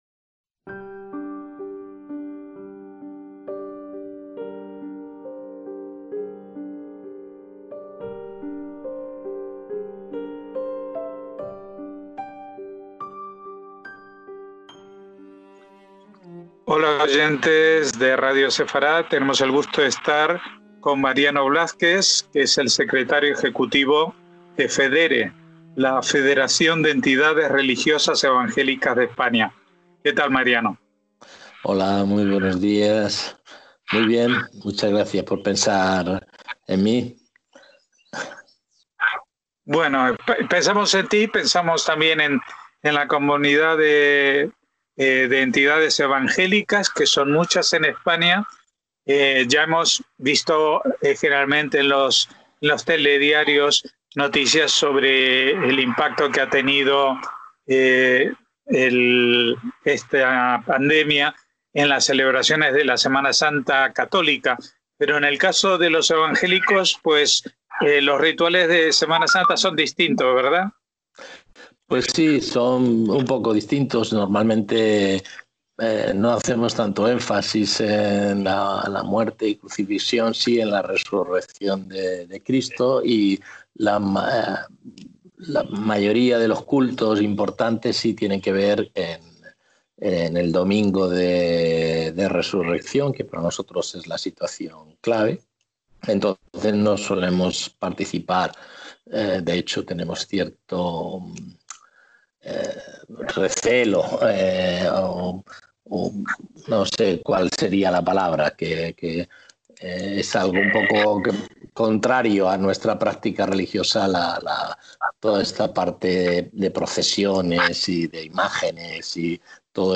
EN DIÁLOGO
Mantuvimos una charla para conocer cómo están llevando sus cuatro mil congregaciones en el país la situación de confinamiento y las perspectivas y problemas que se abren de cara al futuro, una vez superada la crisis sanitaria.